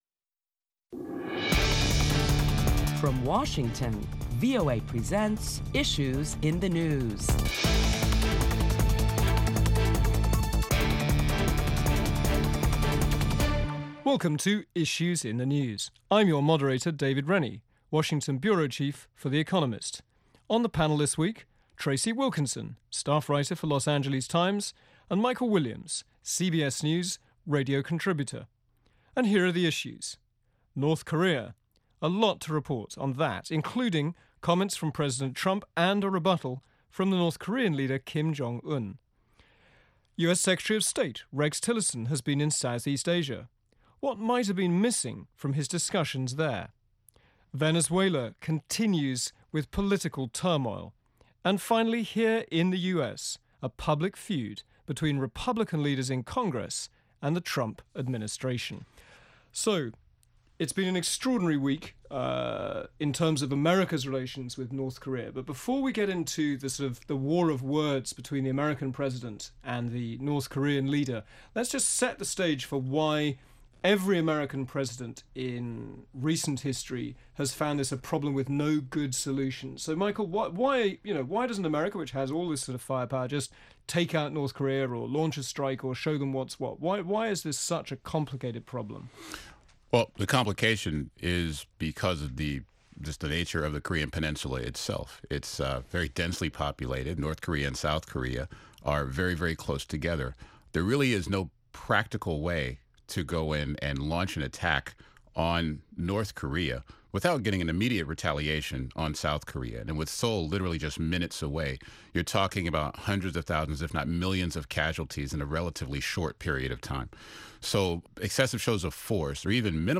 Prominent Washington correspondents discuss the back and forth rhetoric between North Korea and the United States, and Secretary of State Rex Tillerson’s visit to Southeast Asia along with other top stories this week.